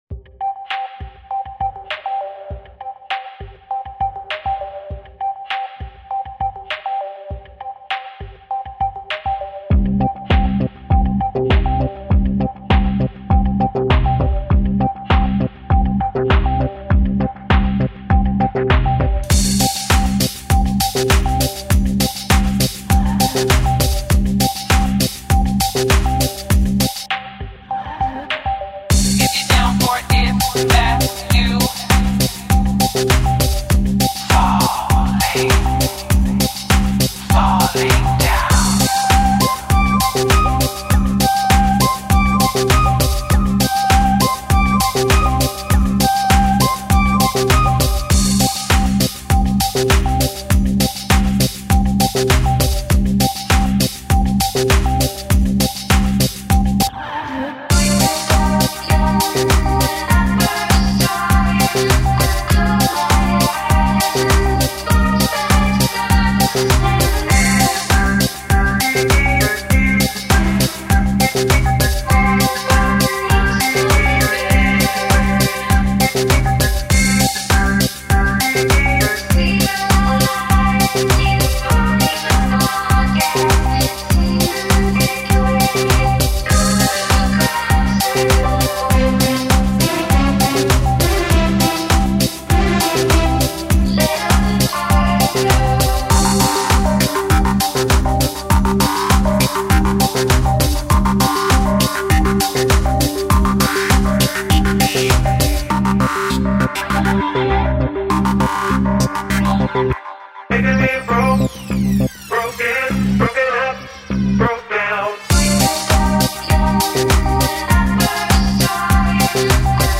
Largo [40-50] plaisir - voix - fete - etrange - club